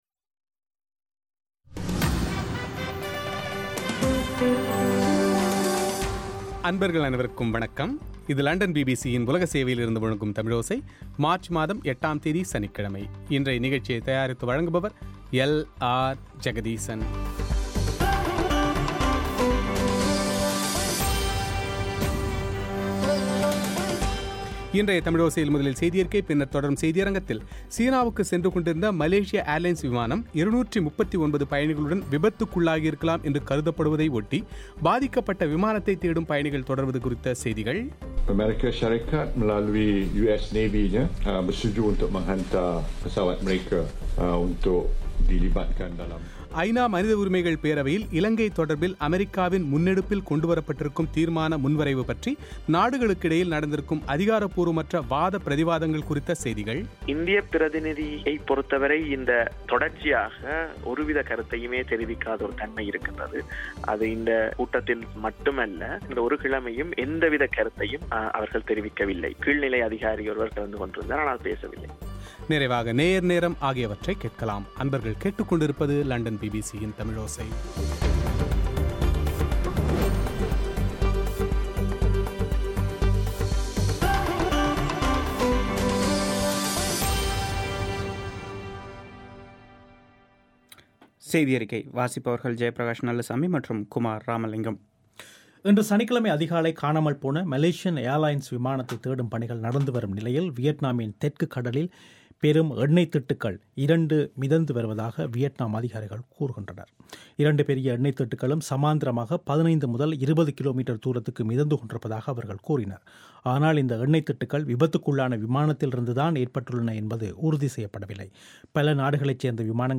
நேரடி கள நிலவரத்தை விளக்கும் செய்திக்குறிப்பு